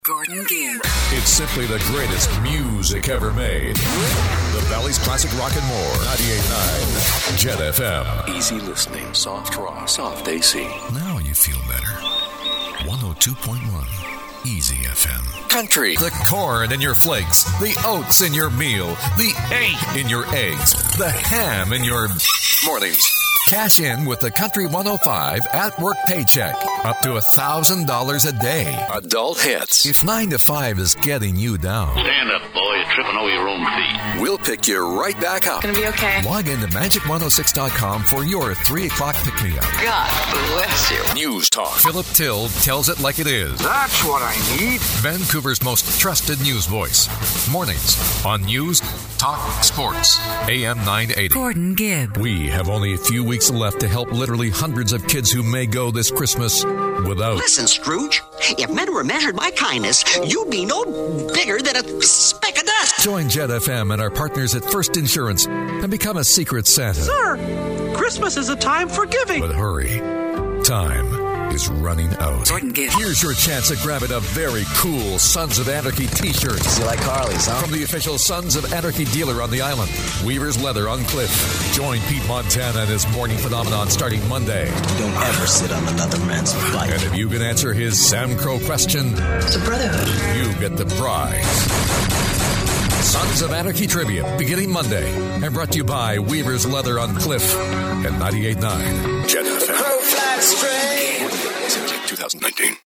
Experience... backed by the hardware For imaging I use the Sennheiser MKH 416 shotgun , the preferred option for radio, network television and movie trailer imaging. The 416 is respected for its capacity to cut through a mix, which is a must for imaging applications.